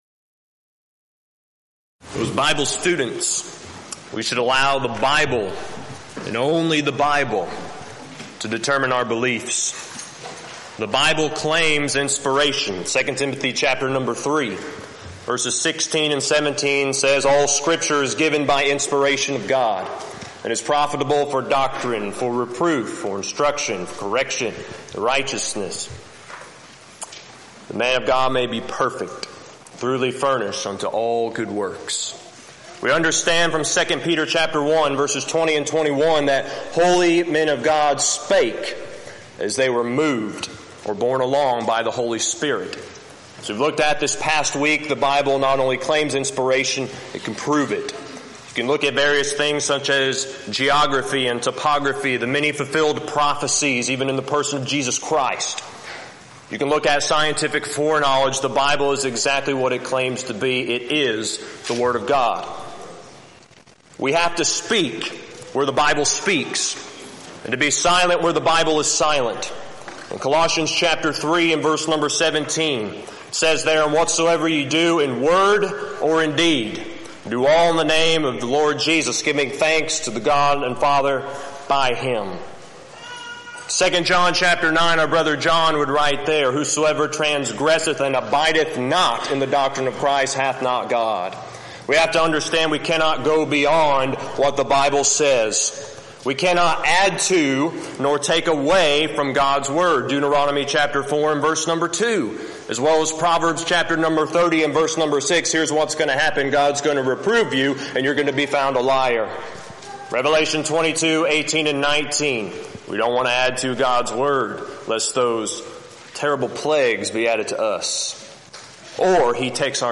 Event: 6th Annual BCS Men's Development Conference
If you would like to order audio or video copies of this lecture, please contact our office and reference asset: 2022MDC07